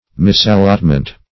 Misallotment \Mis`al*lot"ment\, n. A wrong allotment.